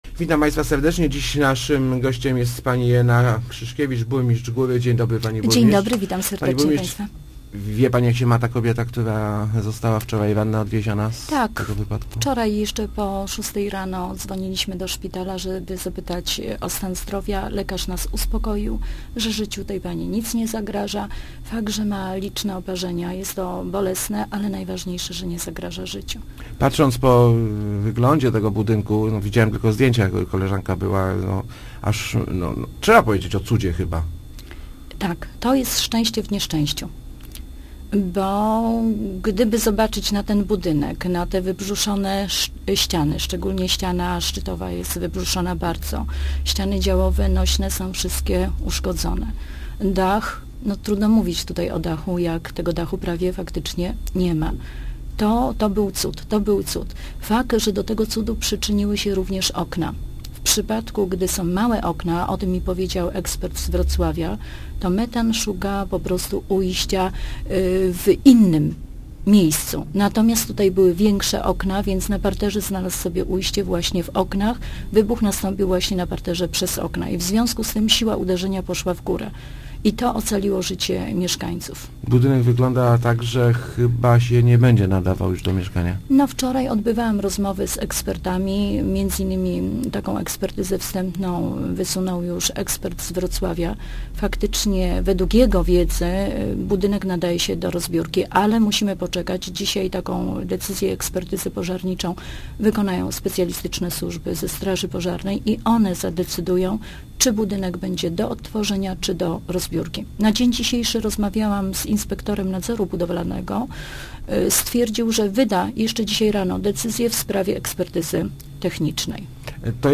Poszkodowani we wczorajszym wybuchu gazu otrzymaj� pomoc samorz�du – zapewni�a w Rozmowach Elki burmistrz Góry Irena Krzyszkiewicz. Cztery rodziny otrzyma�y lokale zast�pcze, poszkodowani dostan� te� pomoc finansow�.